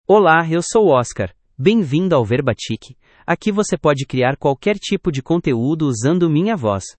Oscar — Male Portuguese (Brazil) AI Voice | TTS, Voice Cloning & Video | Verbatik AI
Oscar is a male AI voice for Portuguese (Brazil).
Voice sample
Male
Oscar delivers clear pronunciation with authentic Brazil Portuguese intonation, making your content sound professionally produced.